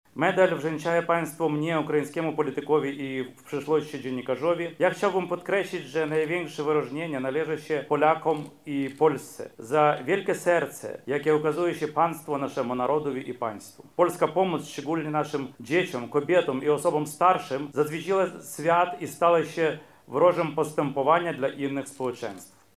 Mykoła Kniażycki– mówi wyróżniony Mykoła Kniażycki.